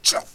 spearman_attack5.wav